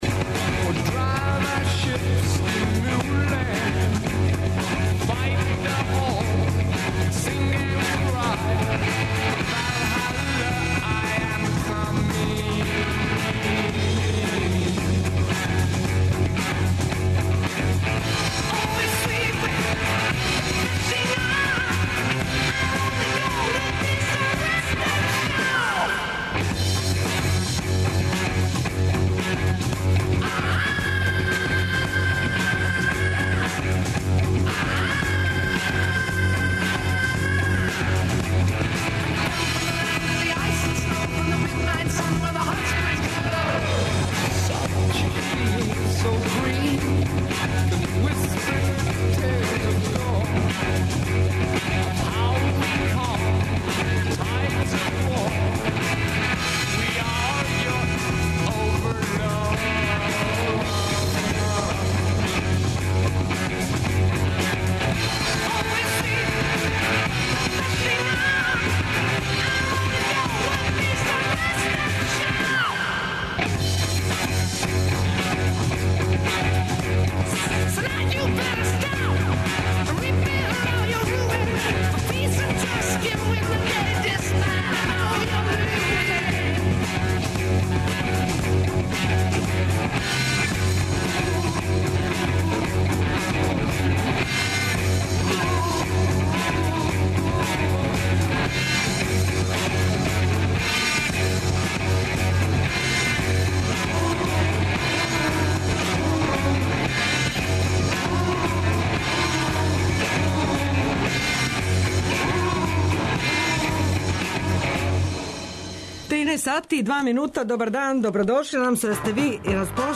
преузми : 26.33 MB Хит недеље Autor: Београд 202 Хит 202, култна емисија 202-јке свакодневно бира хит дана.